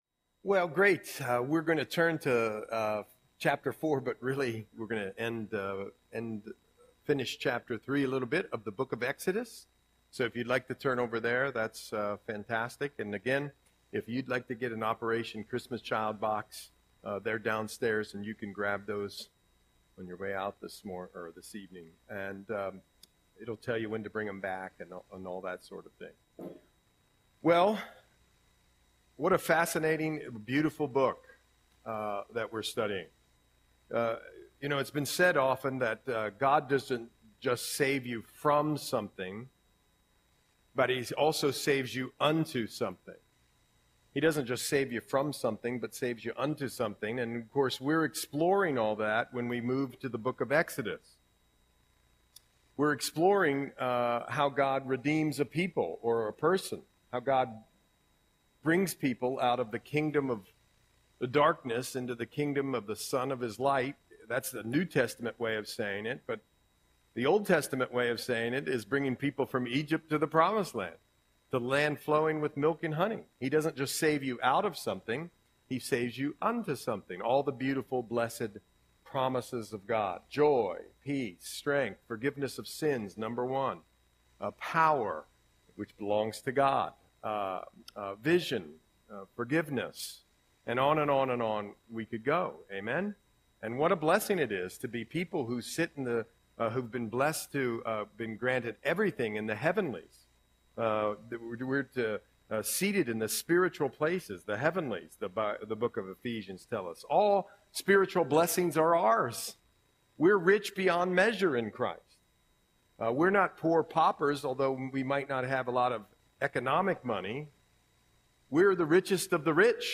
Audio Sermon - November 6, 2024